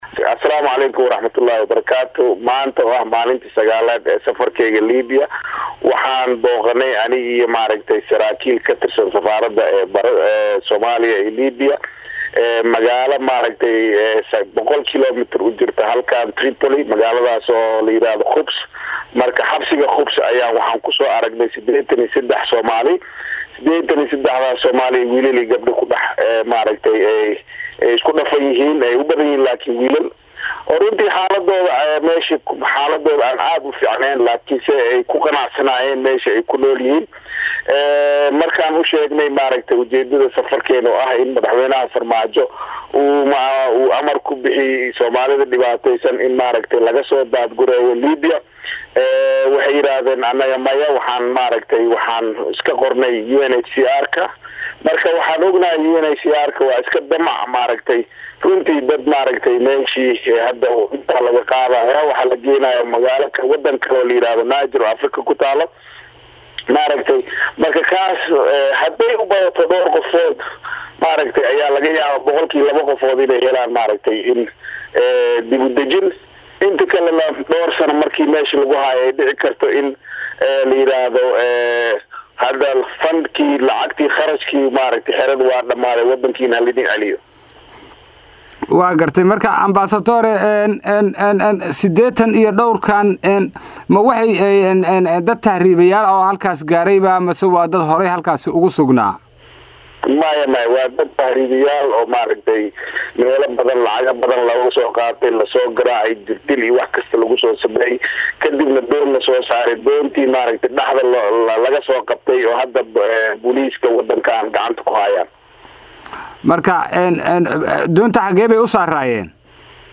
Danjiraha Soomaaliya u fadhiga Midowga Yurub Cali Saciid Fiqi oo la hadlay Radio Muqdisho Codka Jamhuuriyadda Soomaaliya ayaa faah faahin ka